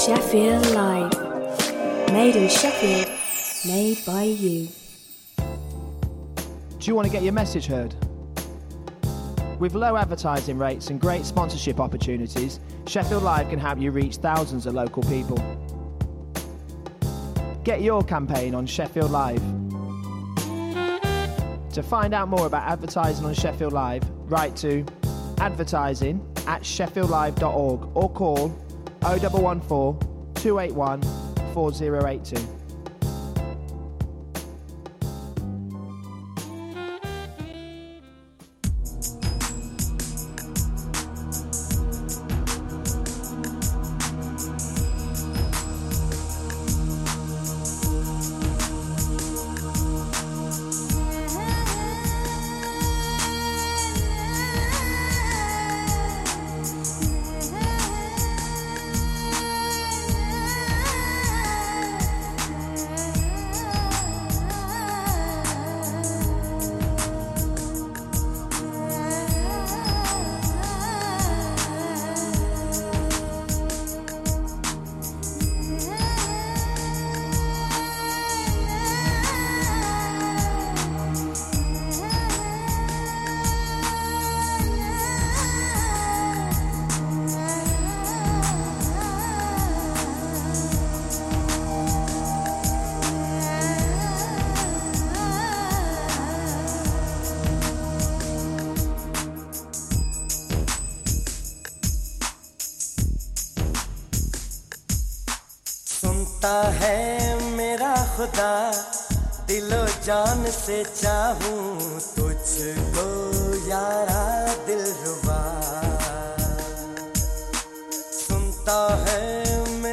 Playing the very best in urban music, new & old…